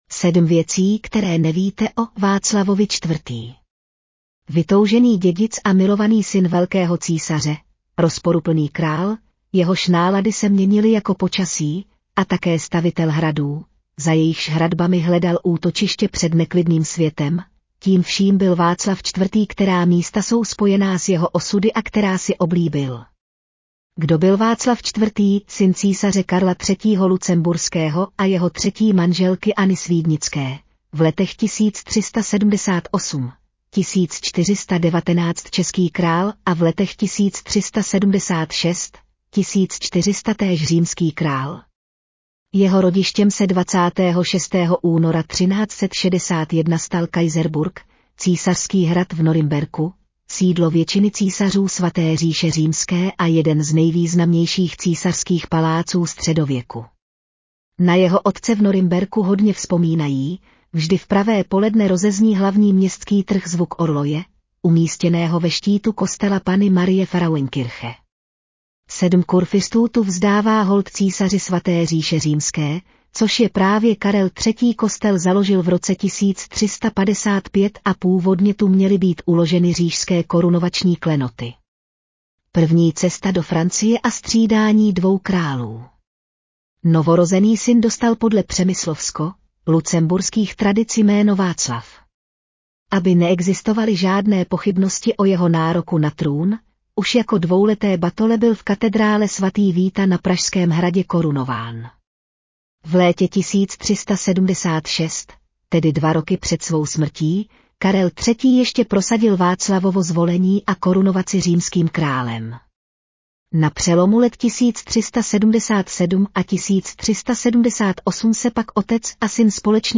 Audio verze článku 7 věcí, které nevíte o... Václavovi IV.